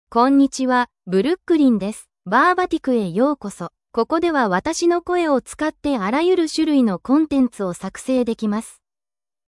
BrooklynFemale Japanese AI voice
Brooklyn is a female AI voice for Japanese (Japan).
Voice sample
Listen to Brooklyn's female Japanese voice.
Brooklyn delivers clear pronunciation with authentic Japan Japanese intonation, making your content sound professionally produced.